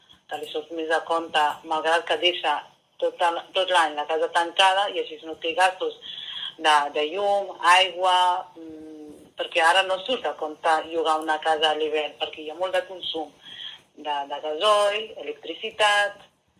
Una de les causes és que els propietaris prefereixen llogar estacionalment perquè els hi surt més a compte. Ho explica Eugeni Pibernat, regidor de turisme a Begur.